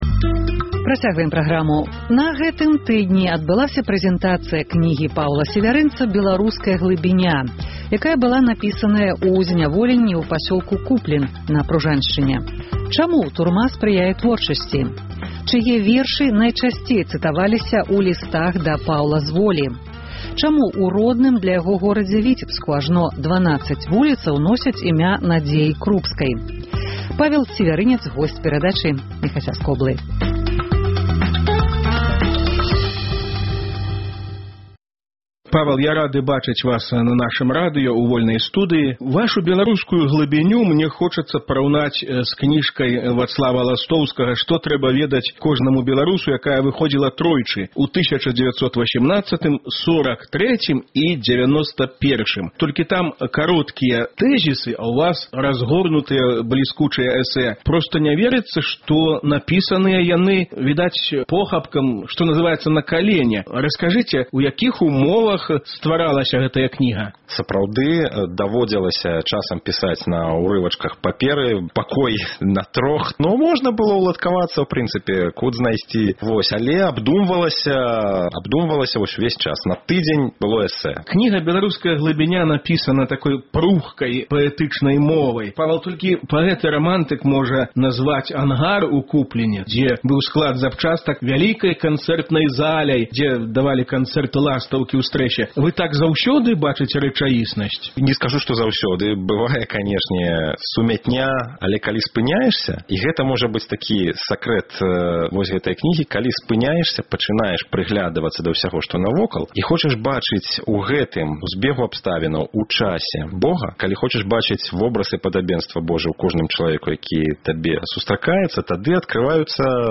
Гутаркі